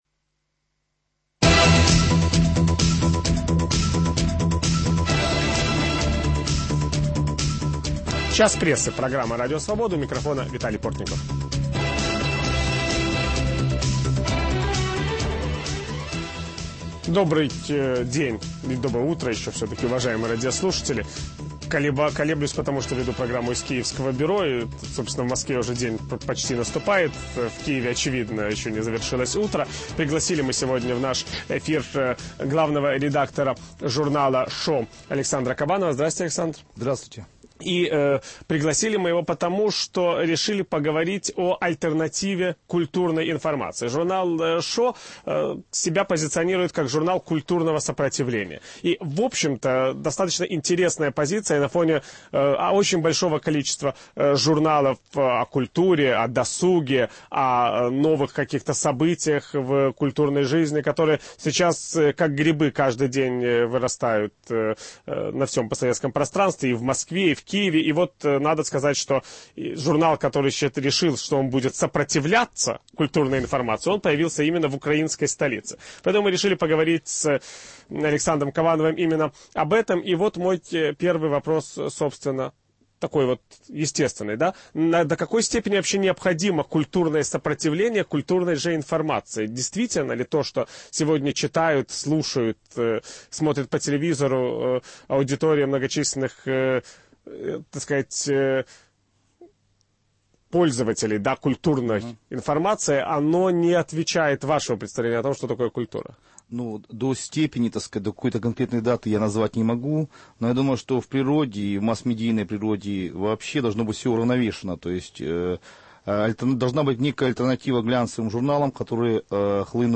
Культурная альтернатива в СМИ. В киевской студии Радио Свобода